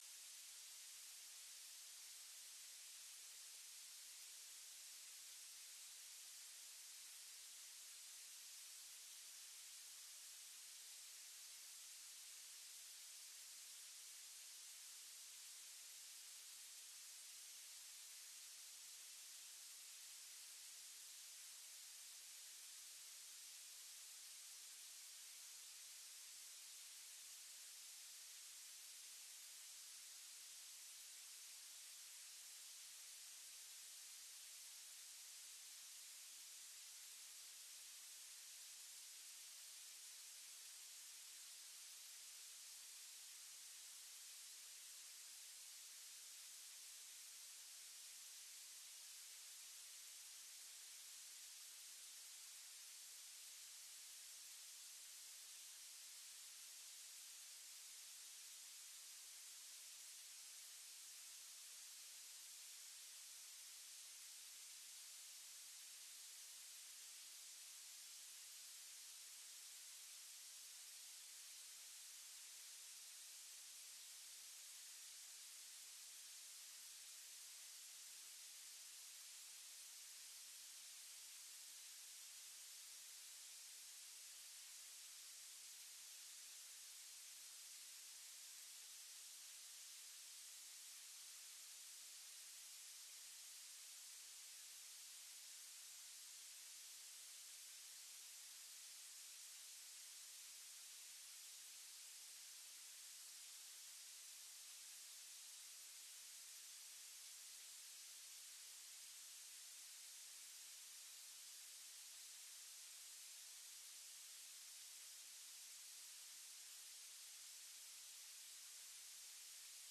Download de volledige audio van deze vergadering
Portefeuillehouder: wethouder Van der Star